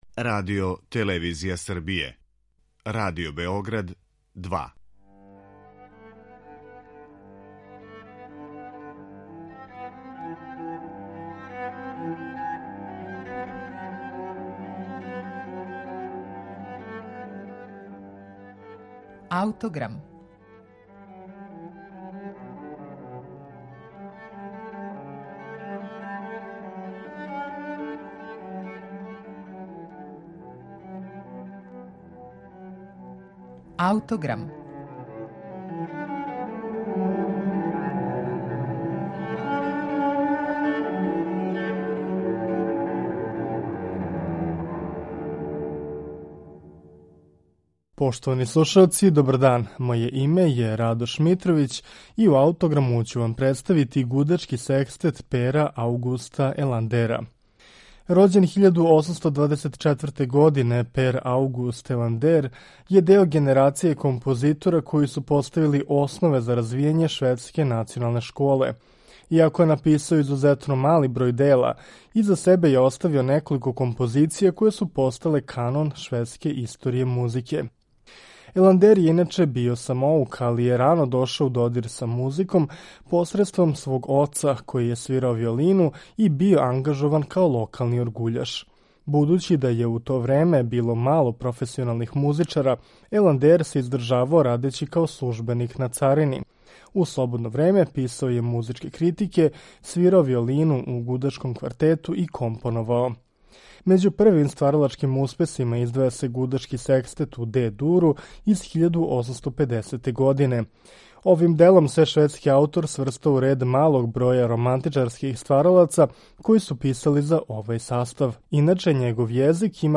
Аугуст Еландер: Гудачки секстет
Посебно се издваја секстет који садржи несумњиви утицај Менделсона и ранороматичарског језика, са елементима аутентичног израза овог мање познатог ствараоца. Секстет слушамо у извођењу Камерних солиста из Упсале.